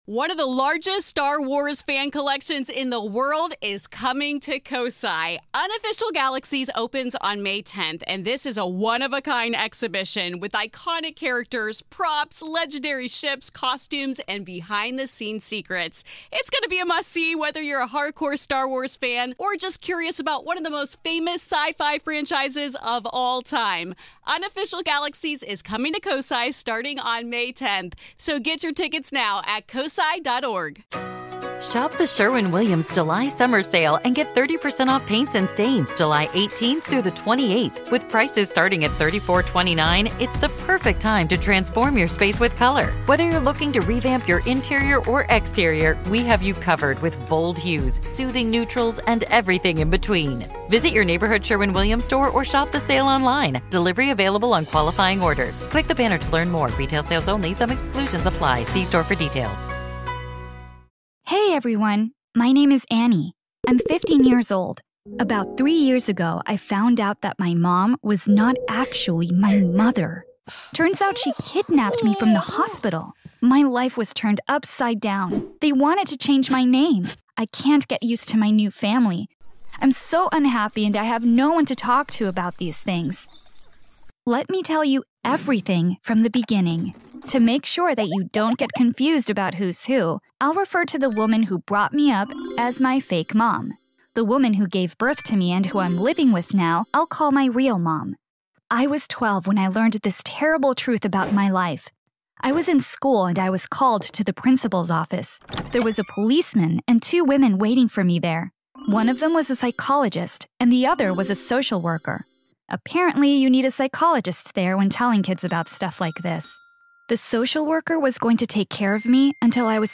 Join us as we explore the incredible journey of survival, identity, and resilience that followed. Through heartfelt narration and candid interviews, we uncover the profound impact of this traumatic event and the quest for truth and reunion.